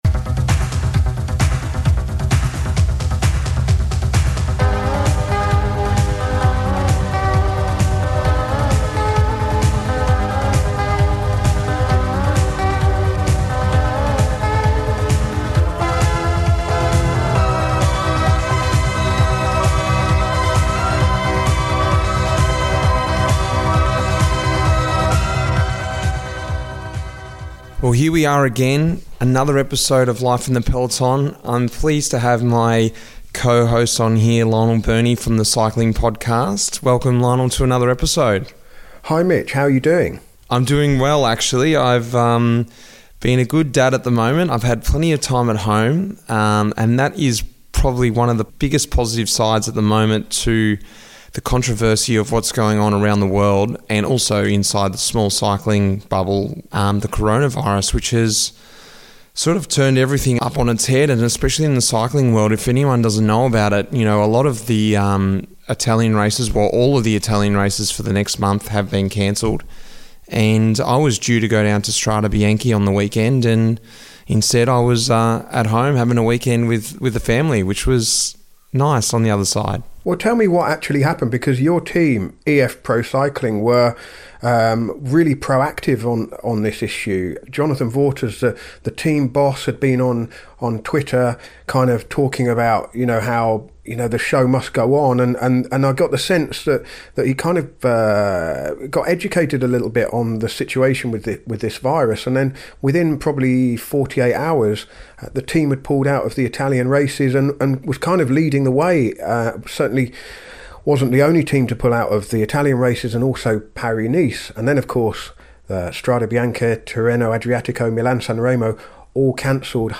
Life in the Peloton is a packed episode of two halves this time as Mitch Docker and his good mate Luke Durbridge look back at the first Belgian Classics of the season before answering some great listeners' questions.